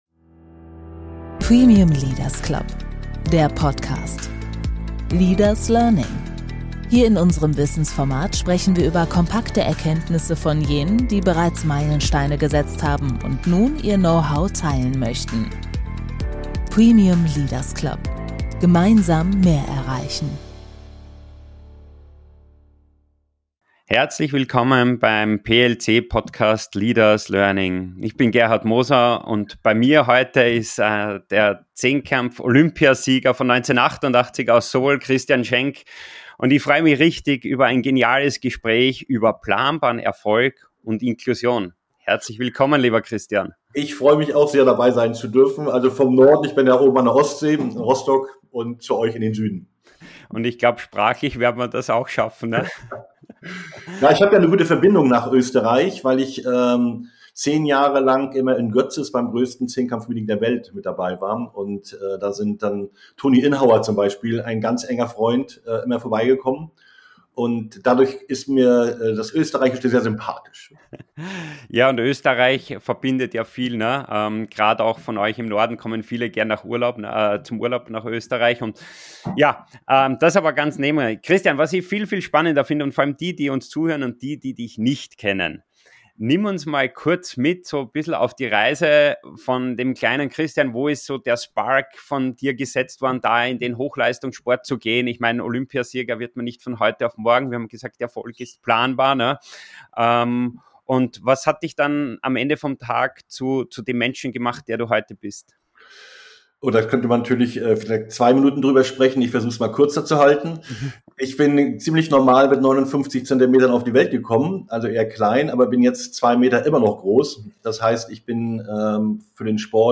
Ein bewegendes Gespräch über die Kraft des Wiederaufstehens, über Inklusion als gelebte Haltung – und darüber, warum echte Stärke oft dann beginnt, wenn man vermeintlich scheitert.